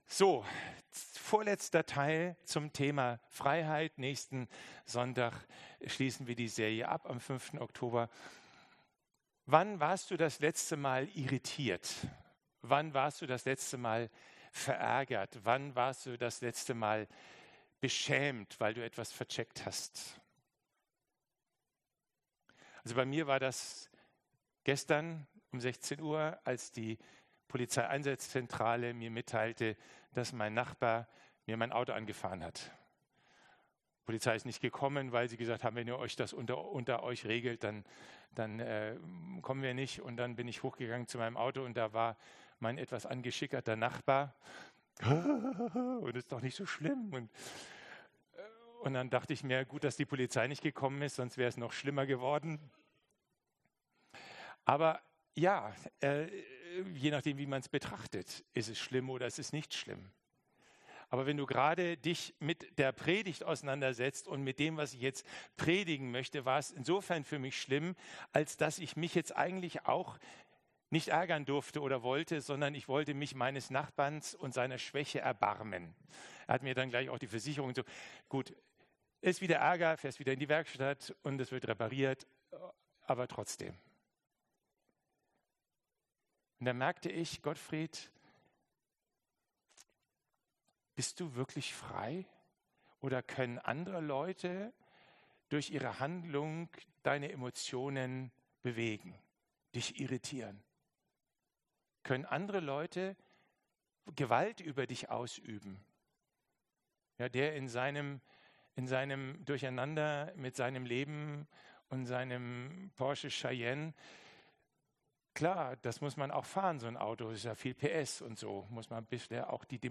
Zusammenfassung der Predigt Einstieg & Alltag